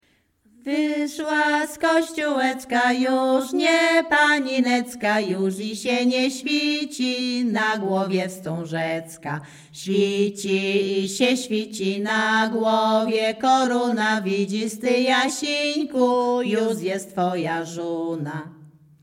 Śpiewaczki z Chojnego
województwo łódzkie, powiat sieradzki, gmina Sieradz, wieś Chojne
Weselna
kupalskie miłosne weselne wesele przyśpiewki